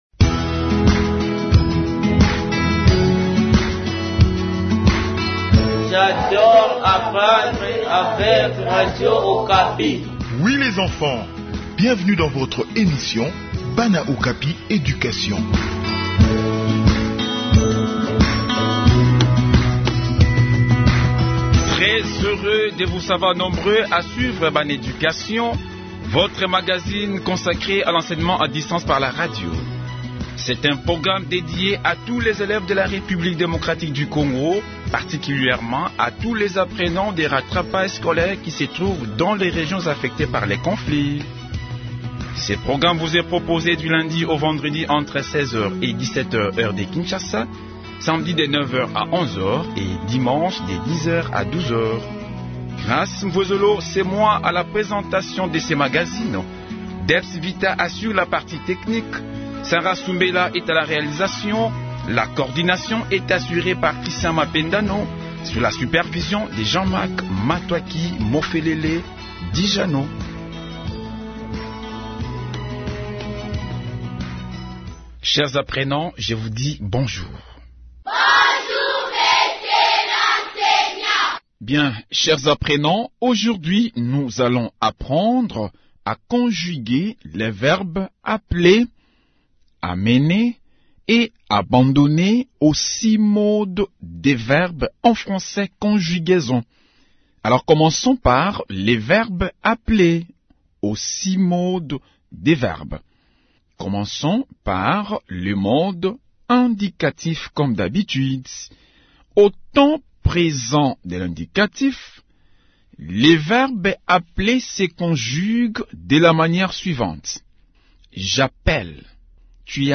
Réécoutez l'intégralité de cette leçon pour en apprendre davantage.